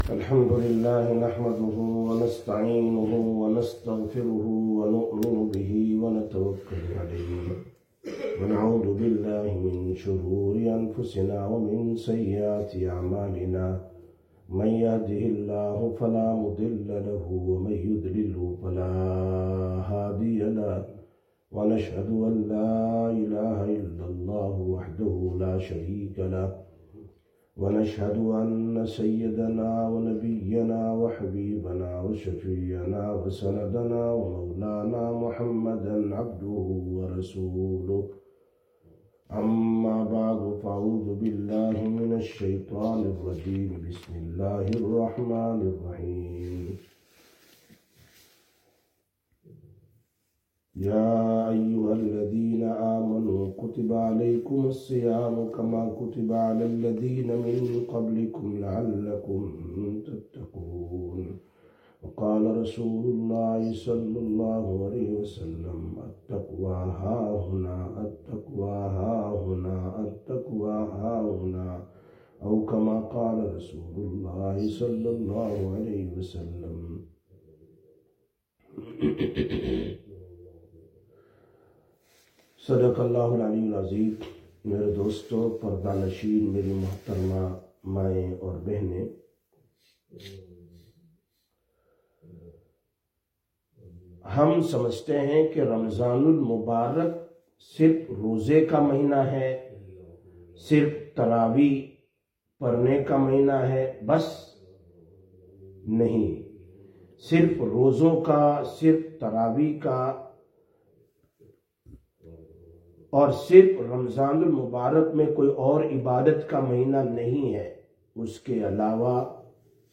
25/02/2026 Sisters Bayan, Masjid Quba